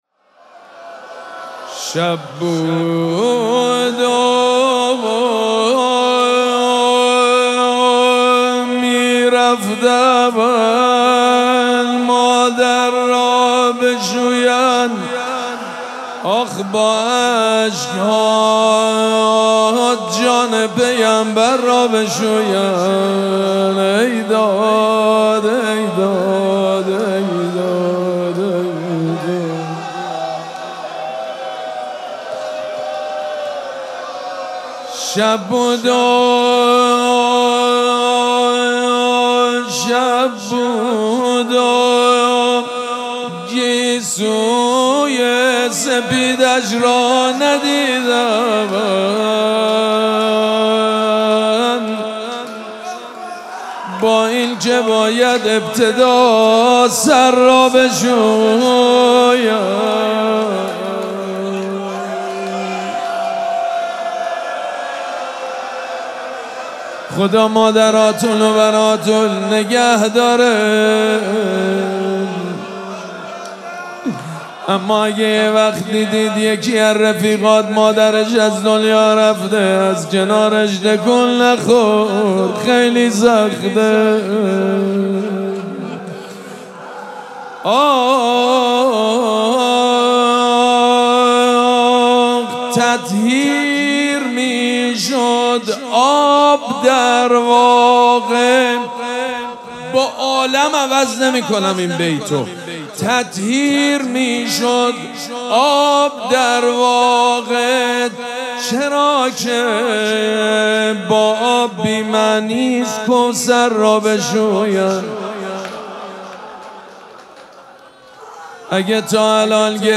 شب پنجم مراسم عزاداری دهه دوم فاطمیه ۱۴۴۶
روضه
مداح